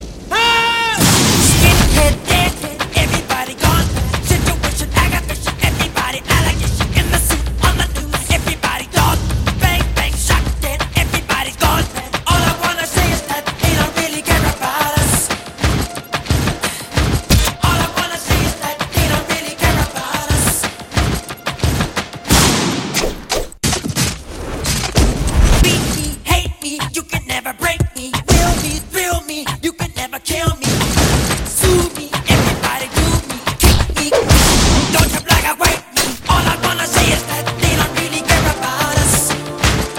ретро
dancehall